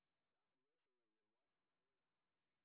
sp23_street_snr0.wav